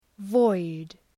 Προφορά
{vɔıd}